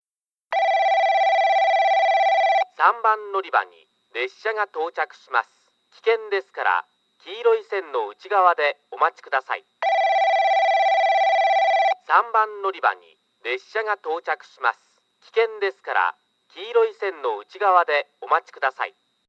3番のりば　接近放送　男声   放送はJACROS簡易型で、のりばパーツが旧タイプでした。
スピーカーはTOA防滴型、TOAラッパ型でした。